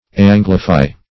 Search Result for " anglify" : The Collaborative International Dictionary of English v.0.48: Anglify \An"gli*fy\, v. t. [imp.